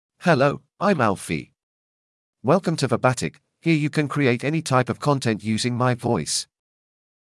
MaleEnglish (United Kingdom)
Alfie is a male AI voice for English (United Kingdom).
Voice sample
Listen to Alfie's male English voice.
Alfie delivers clear pronunciation with authentic United Kingdom English intonation, making your content sound professionally produced.